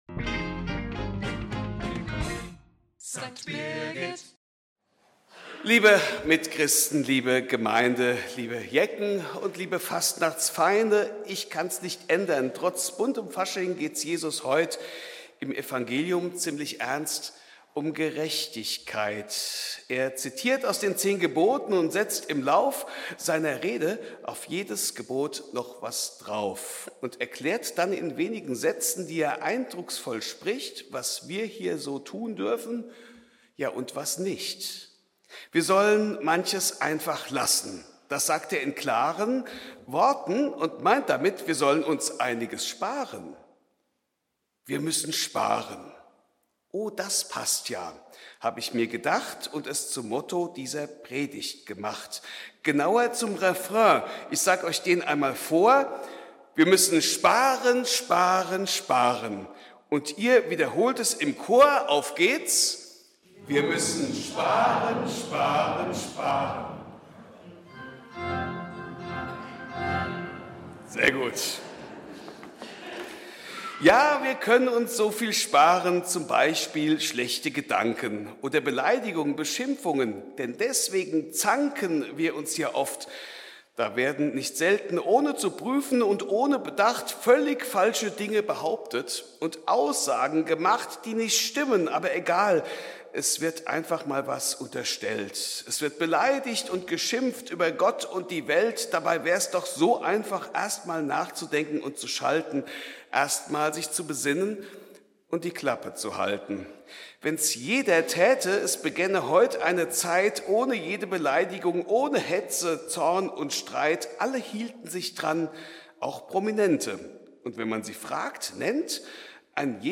Fastnachtspredigt